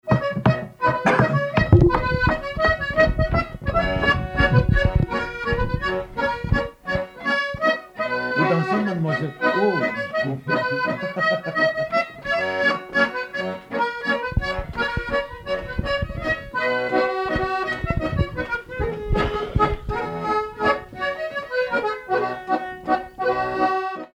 Saint-Nicolas-la-Chapelle
danse : mazurka
circonstance : bal, dancerie
Pièce musicale inédite